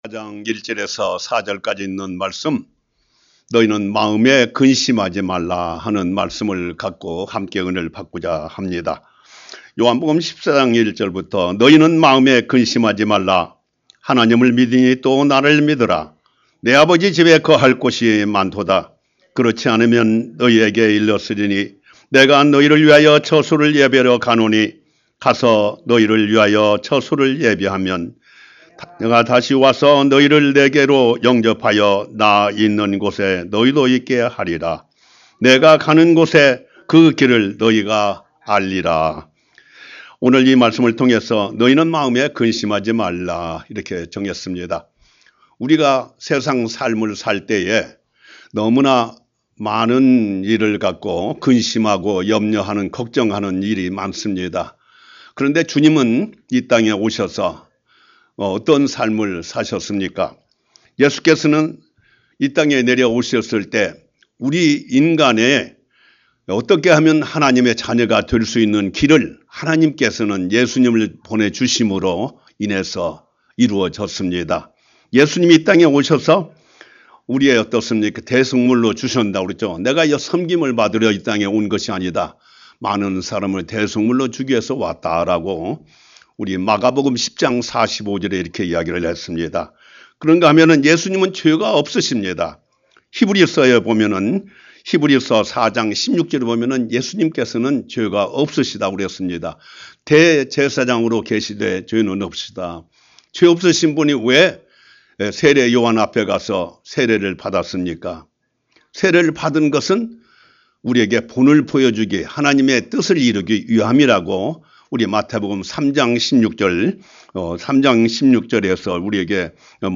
Sermon - 너희는 마음에 근심하지 말라 Don’t worry about anything.